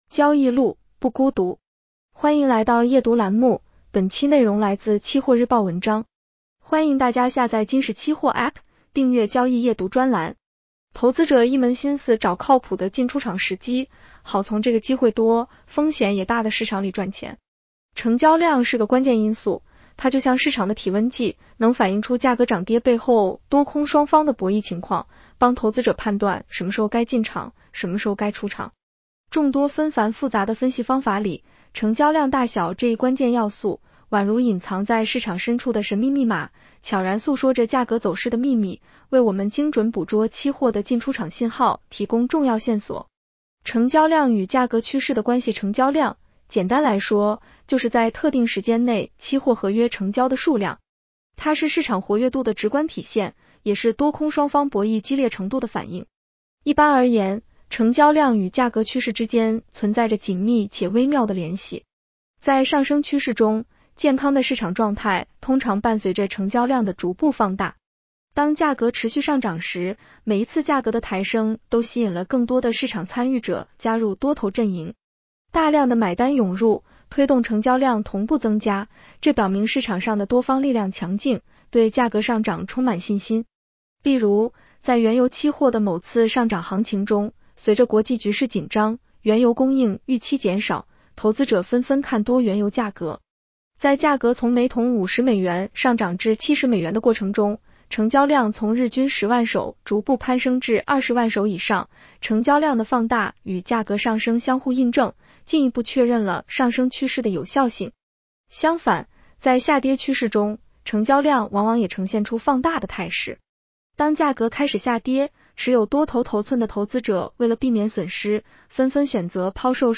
【期货交易夜读音频版】
女声普通话版 下载mp3